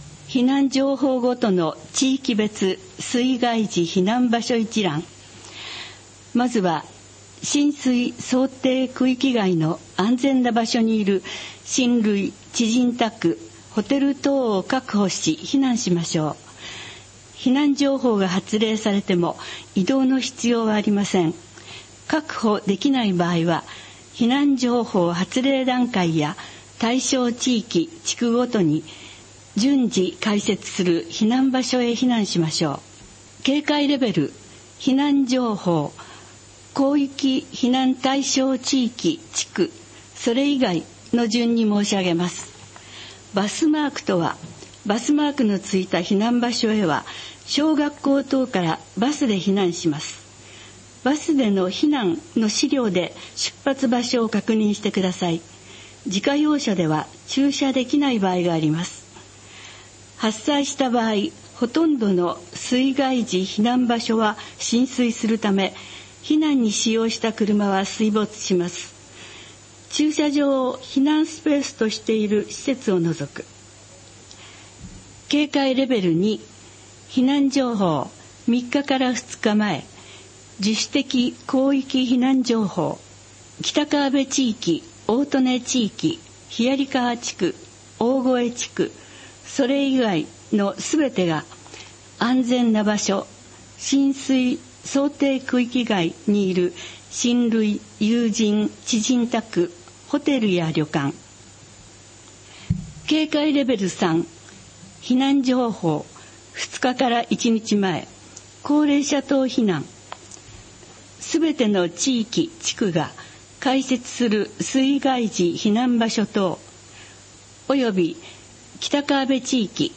【協力】録音：加須市朗読ボランティア「やよい」編集：加須市社会福祉協議会